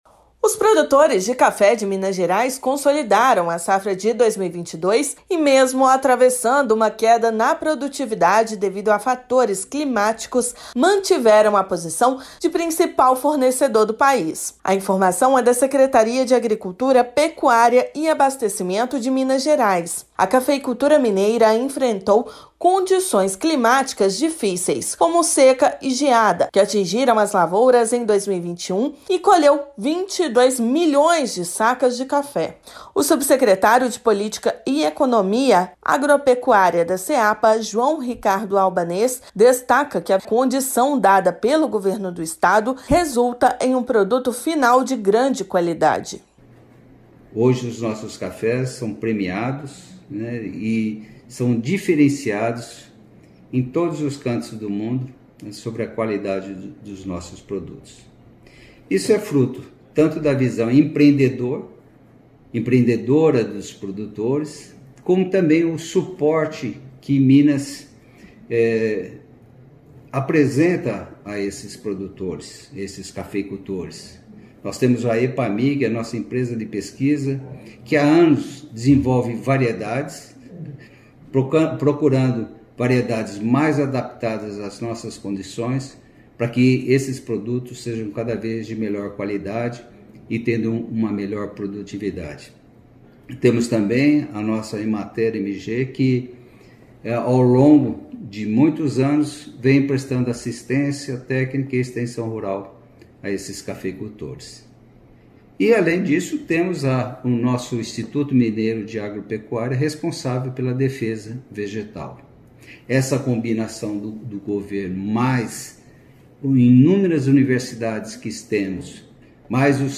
Principal produtor do país, estado recebe, de 16 a 18/11, a 10ª edição da Semana Internacional do Café no Expominas (BH). Ouça a matéria de rádio.